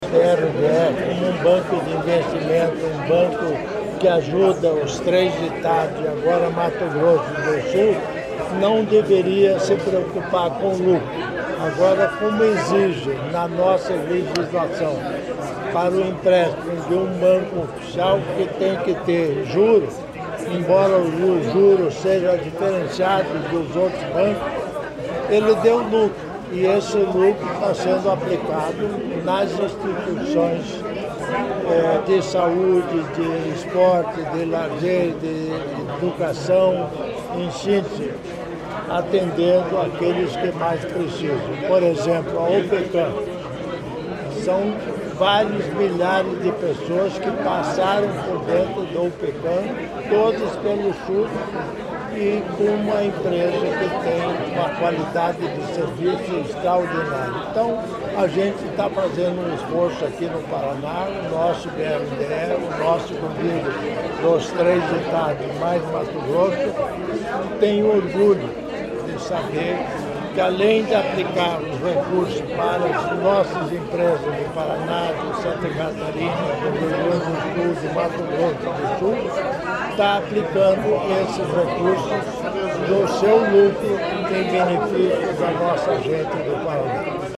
Sonora do vice-governador Darci Piana sobre os repasses do BRDE no Show Rural